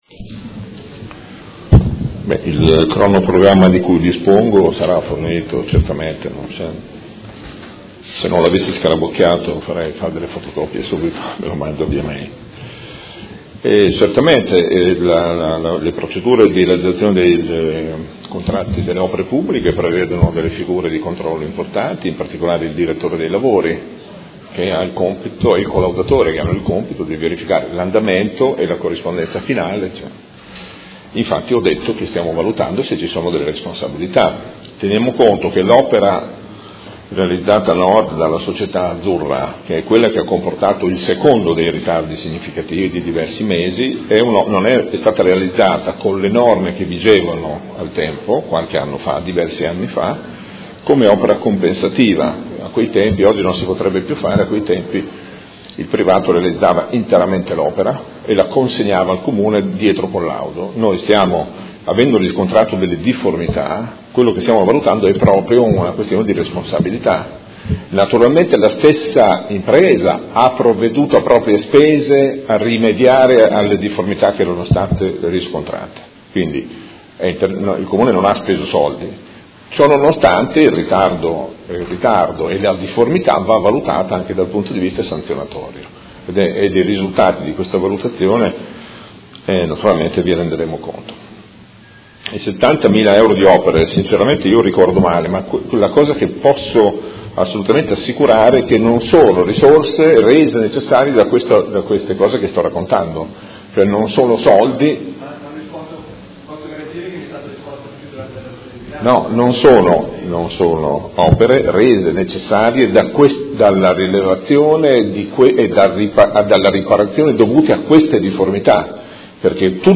Dibattito su interrogazione del Gruppo M5S avente per oggetto: Completamento sottopasso ferroviario tra zona Crocetta (ex BenFra) e Via Scaglietti (ex Acciaierie) a fianco del cavalcavia Ciro Menotti